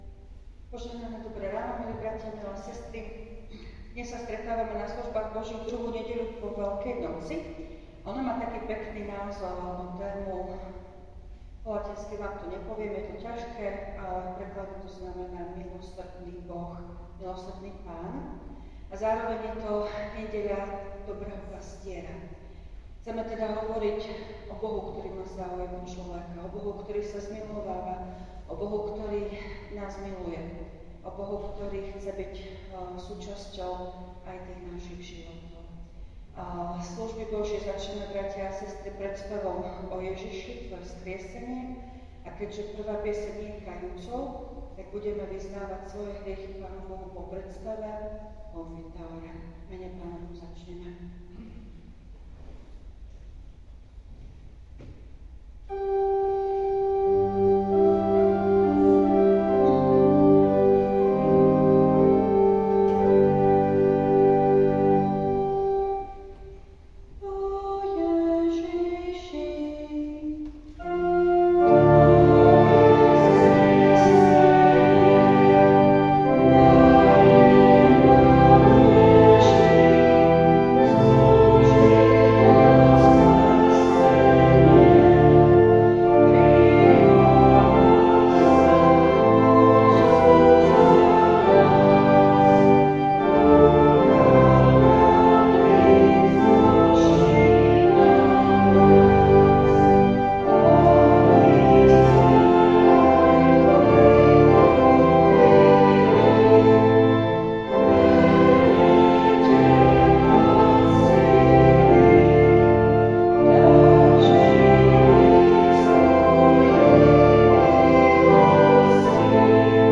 Služby Božie – 2. nedeľa po Veľkej noci
V nasledovnom článku si môžete vypočuť zvukový záznam zo služieb Božích – 2. nedeľa po Veľkej noci.